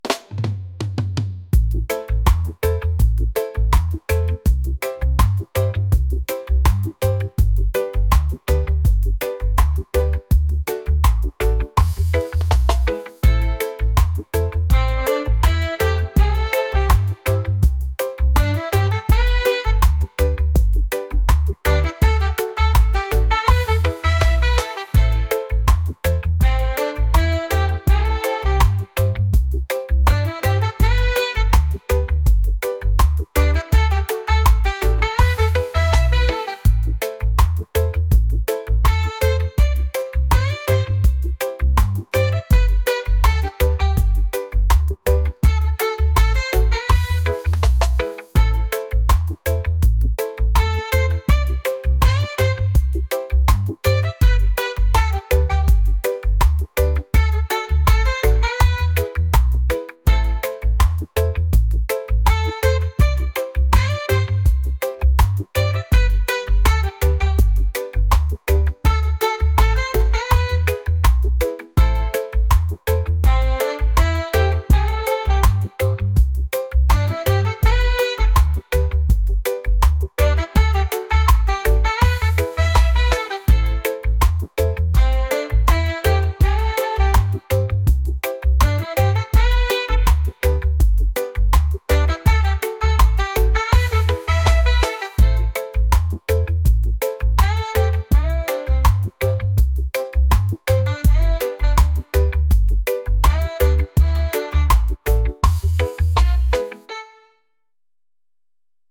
reggae | folk | latin